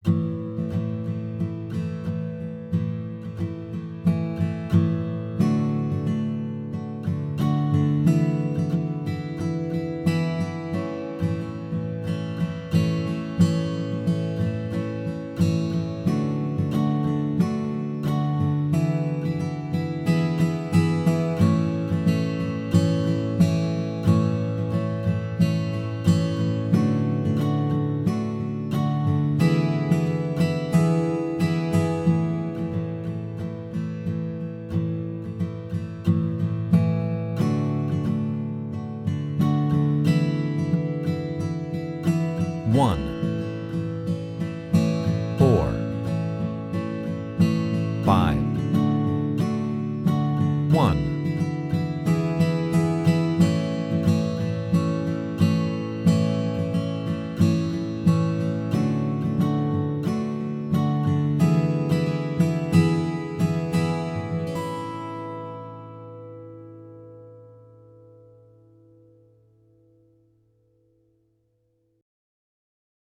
Guitar
Testing 1. I-IV-V-I (A)